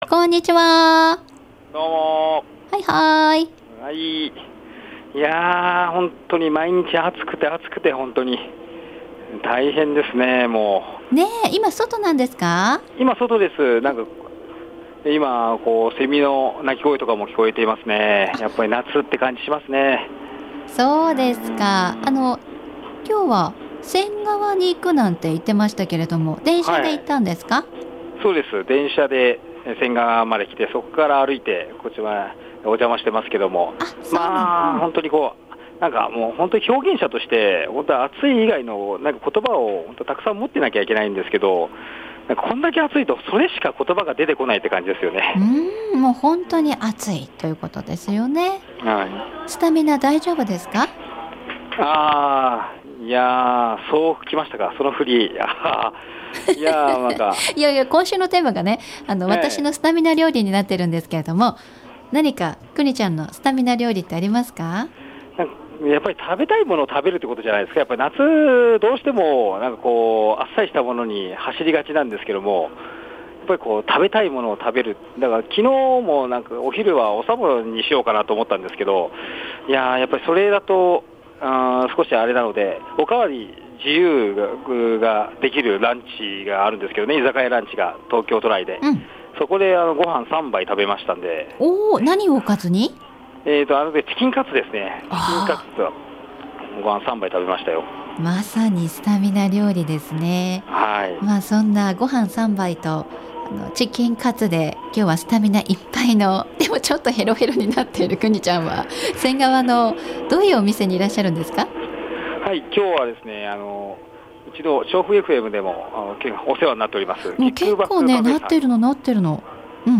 午後のカフェテラス 街角レポート
今日は仙川のKICKBACKCAFEさんにお邪魔しました。